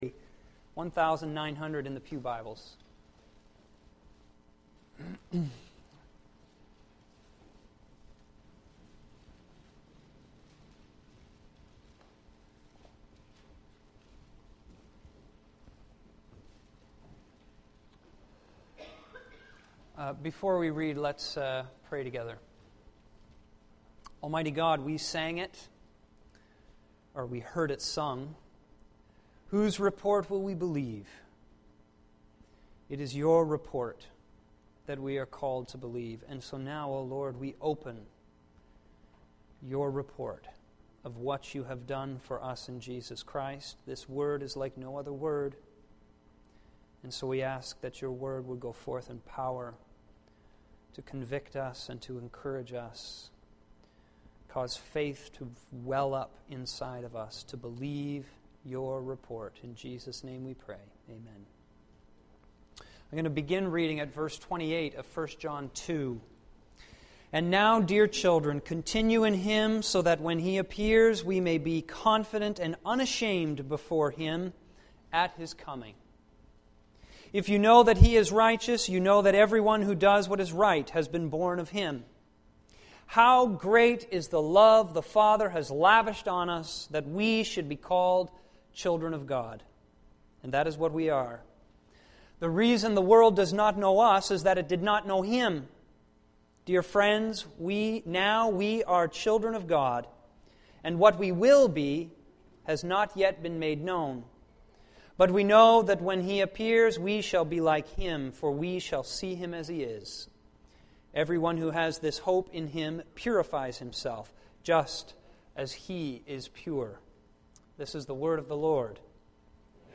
1 John 3:1-5 > We welcome the Teen Challenge choir to our church this evening as they minister to us in songs and testimony. The message will centre on the Fatherhood of God.